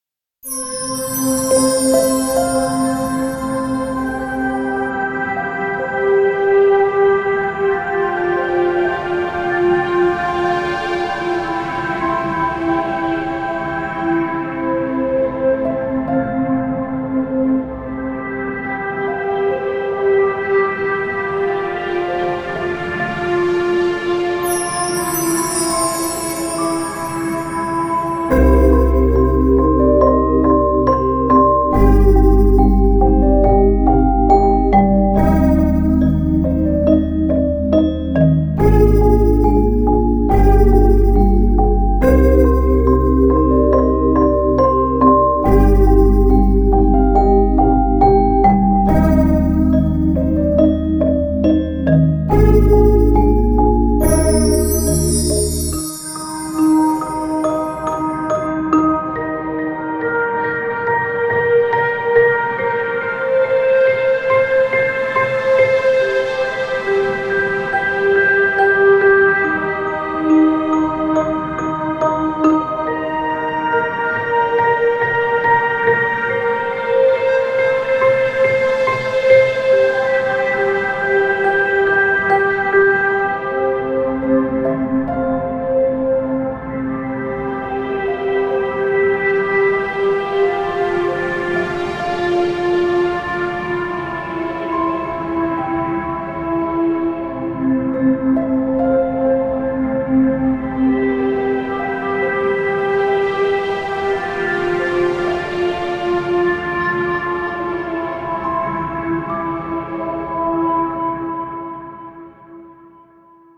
パッドを主体に、ピアノとシンセが重なり合い美しさの中にどこか不安を感じる独特の空気感を表現しています。
• 広がりのあるパッドで世界観を構築
• リバーブを深めに設定し現実離れを演出
oasis1srLOOP OGG